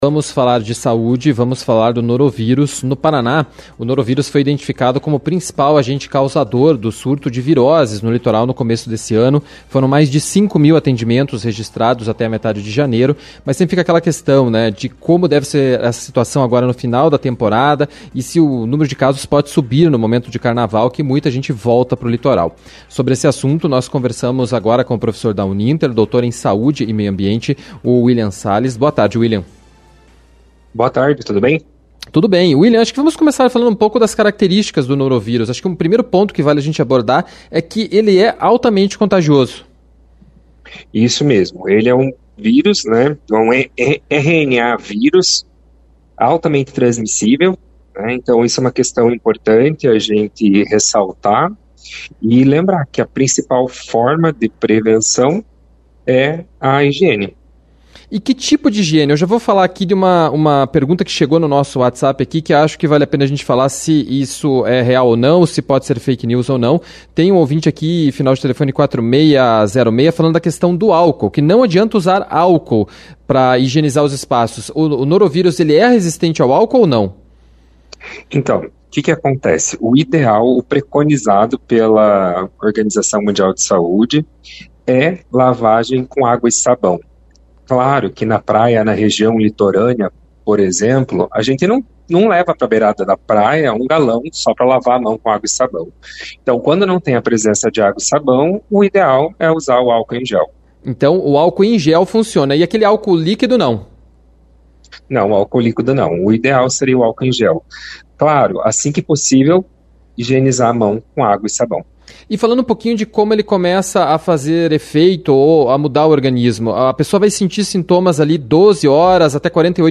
ENTREVISTA-15H.mp3